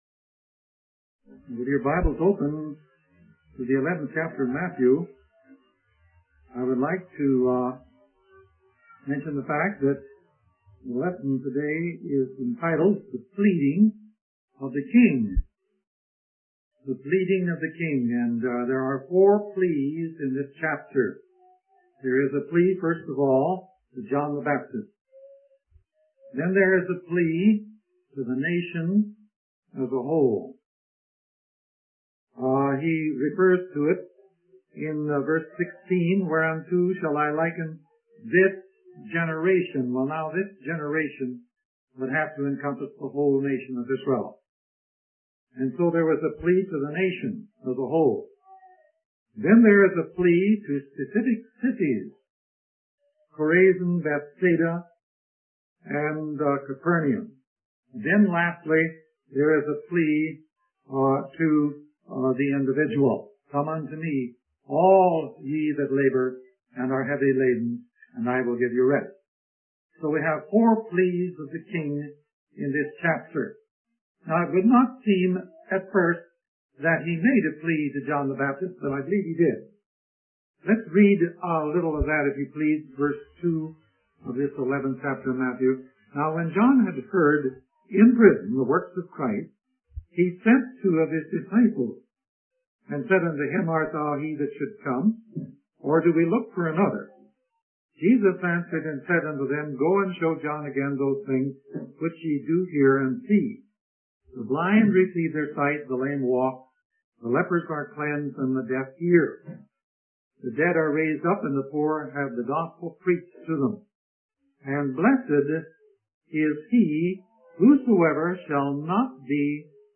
In this sermon, the speaker discusses the unresponsiveness of the Jewish people to the witness of John the Baptist and Jesus.